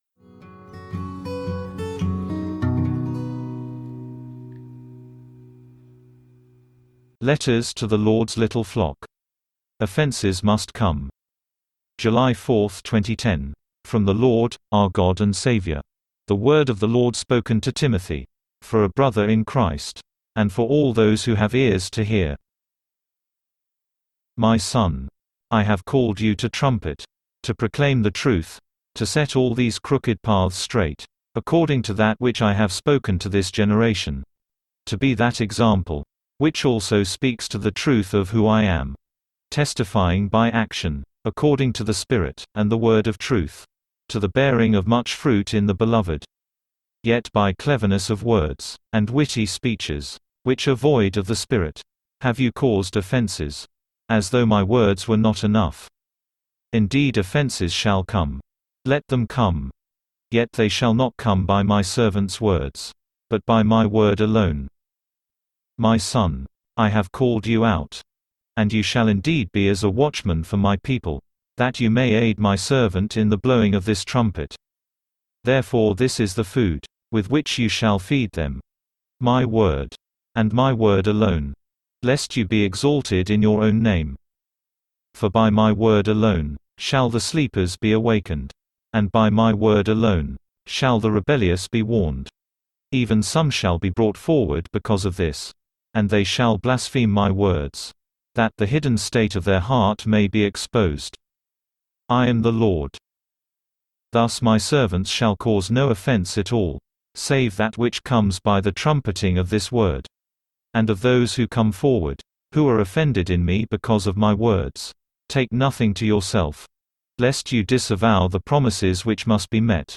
File:OFV.029 Offenses Must Come (read by text-to-speech).mp3 - The Volumes of Truth
OFV.029_Offenses_Must_Come_(read_by_text-to-speech).mp3